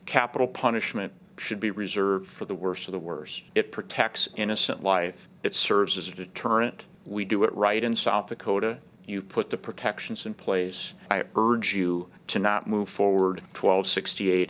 Attorney General Marty Jackley led the opposition.